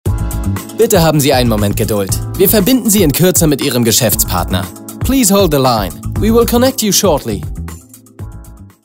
Gerade eben wurden die neuen Telefonansagen für LLOYD Schuhe fertig gestellt und freigeschaltet.
LLOYD wünschte sich eine frische, dynamische und unverbrauchte Stimme.